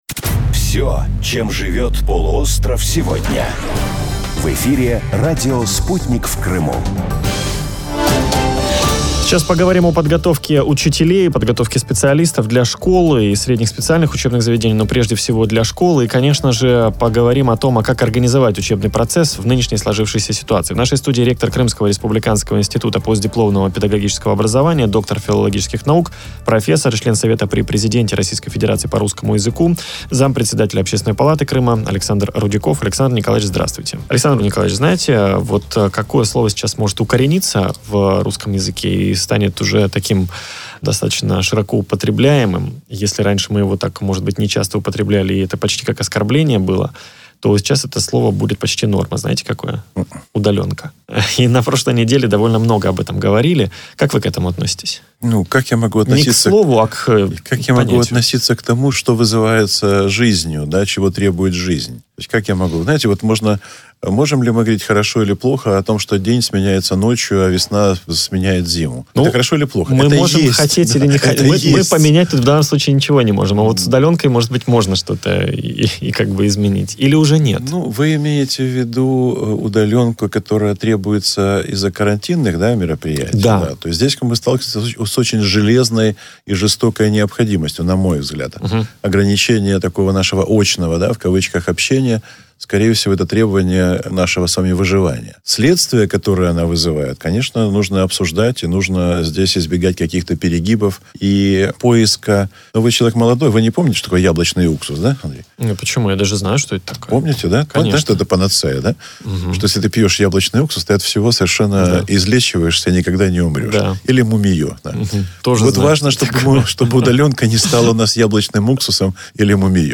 Запись эфира на радио «Спутник в Крыму»